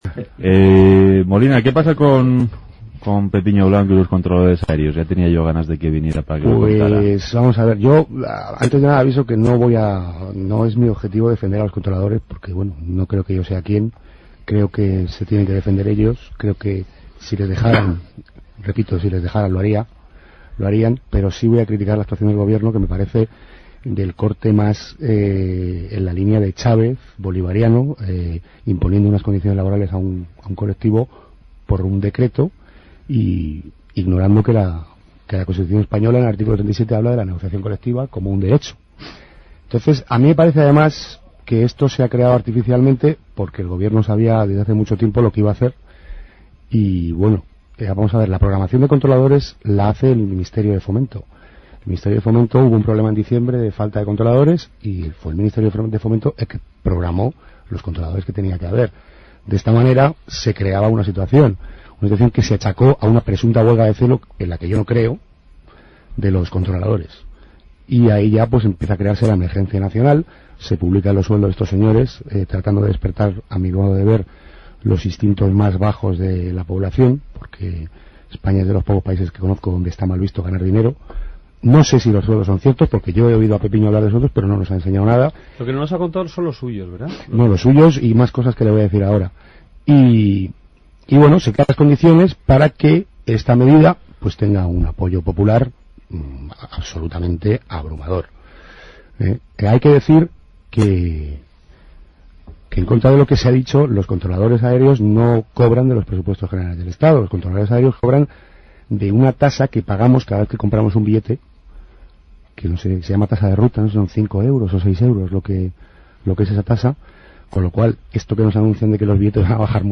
Controladores aéreos,  Intervenciones en los Medios,  Opinión,  Portada